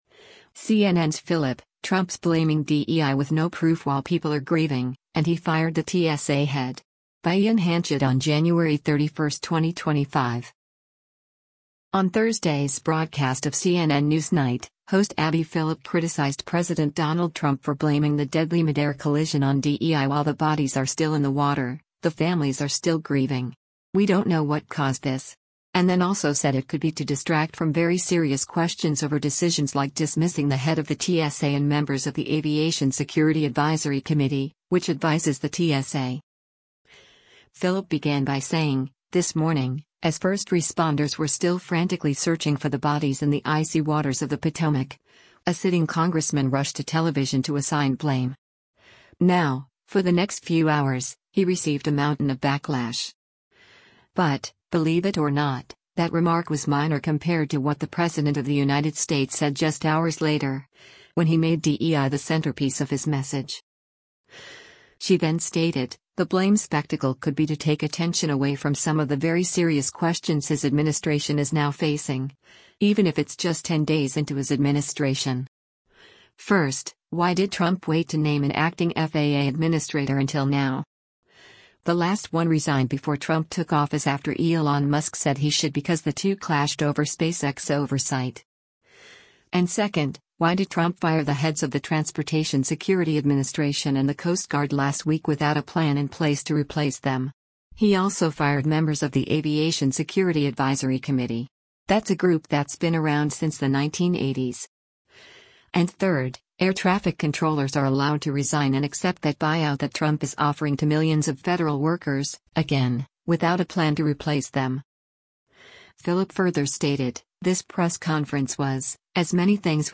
On Thursday’s broadcast of “CNN NewsNight,” host Abby Phillip criticized President Donald Trump for blaming the deadly midair collision on DEI while “The bodies are still in the water, the families are still grieving. We don’t know what caused this.” And then also said it could be to distract from “very serious questions” over decisions like dismissing the head of the TSA and members of the Aviation Security Advisory Committee, which advises the TSA.